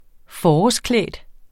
Udtale [ -ˌklεˀd ]